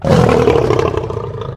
lion2.ogg